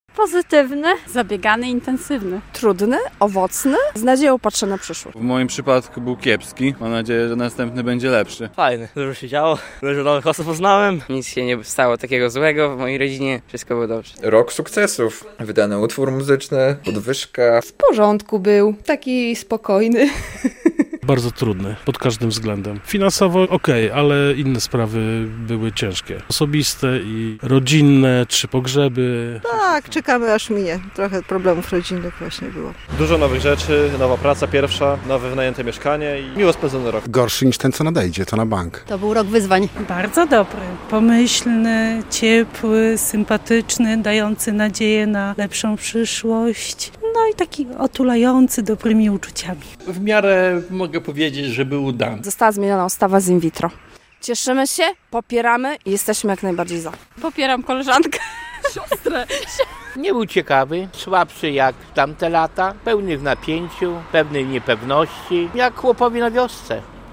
Jaki był ten stary rok? - relacja